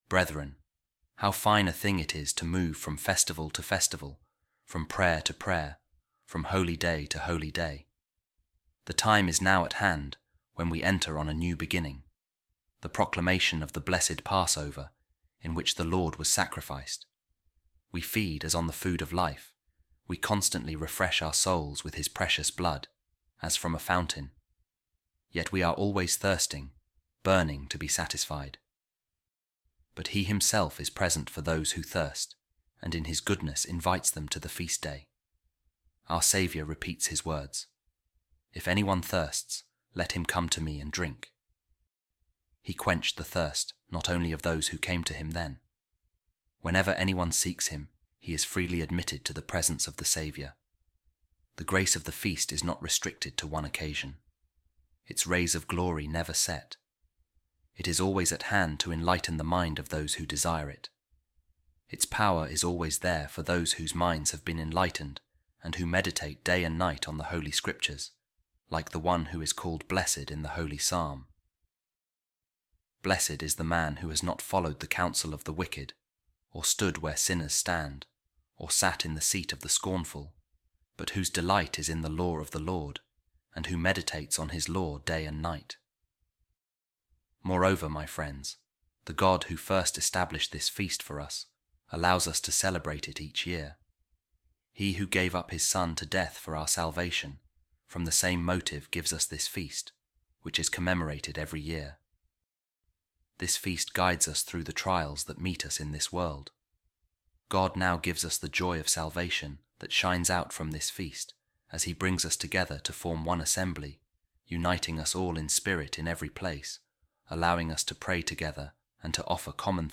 A Reading From The Easter Letters Of Saint Athanasius